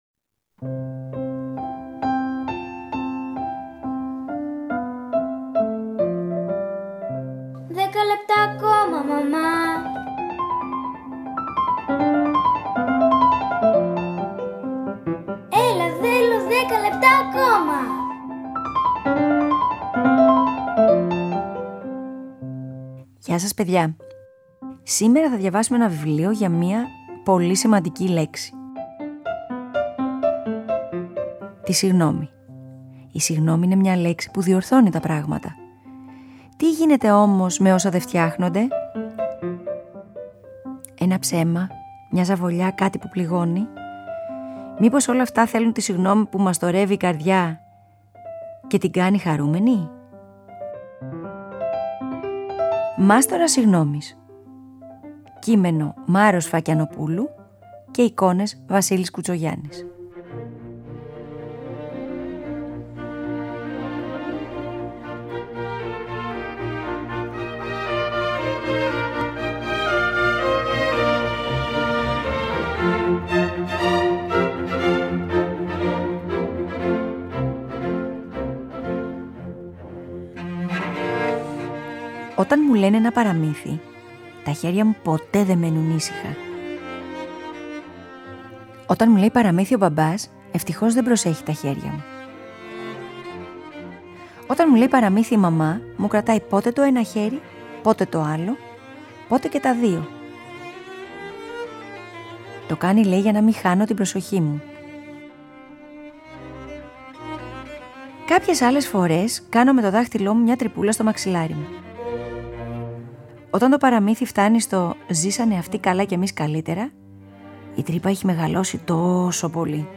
Cello concerto in B flat Major, G.482, I. Allegro Moderato